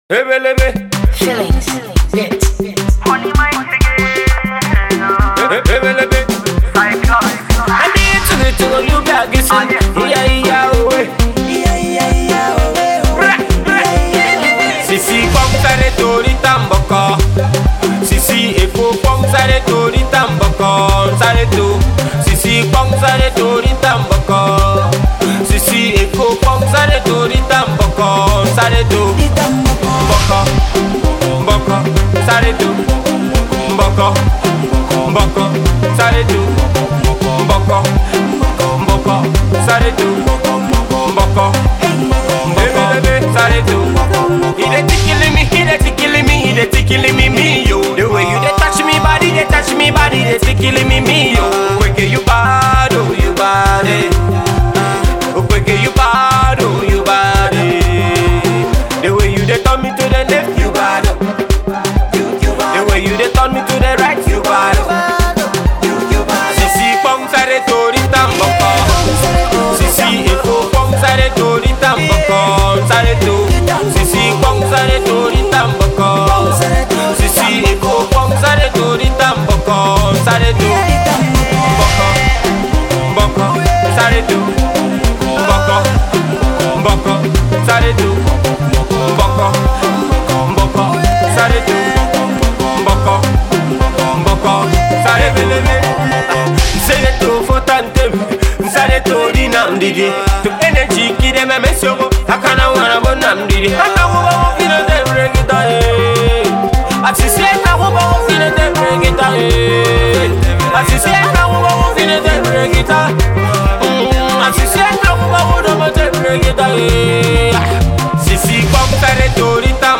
Indigenous Pop
Pop Track